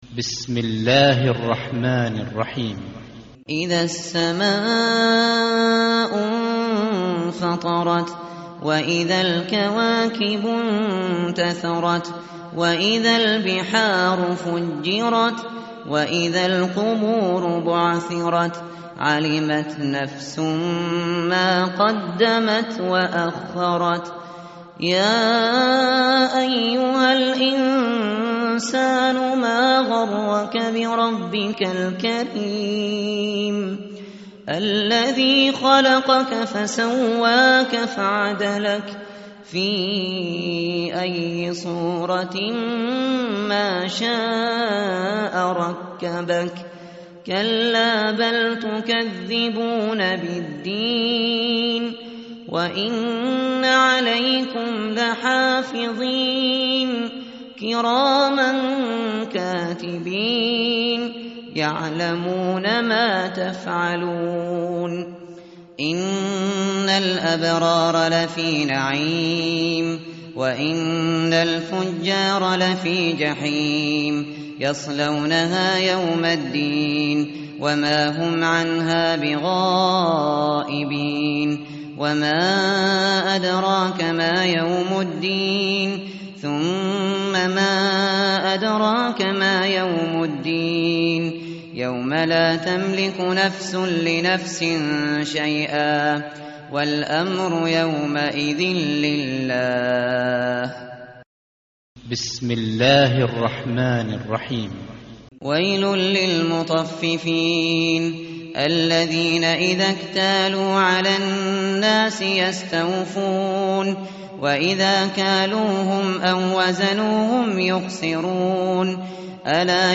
tartil_shateri_page_587.mp3